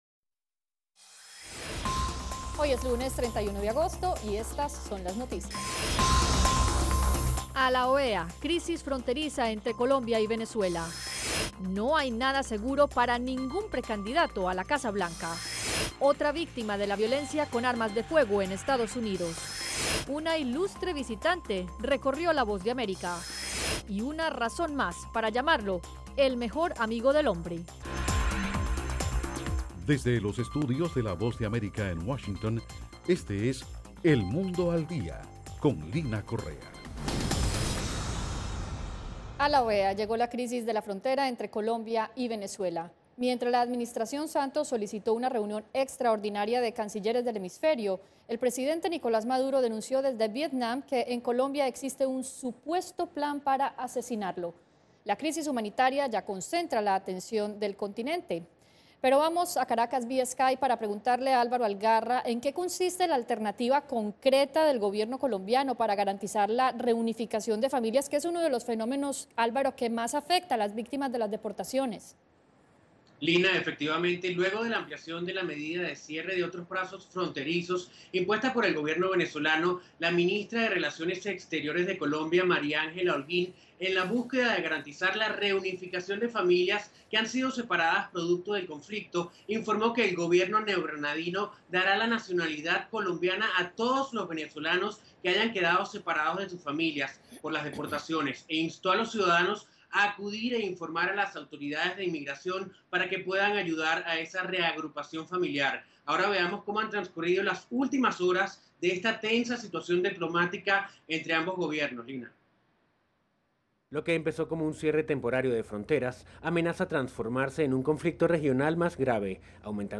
Una transmisión simultanea del noticiero de televisión “El mundo al día” en radio.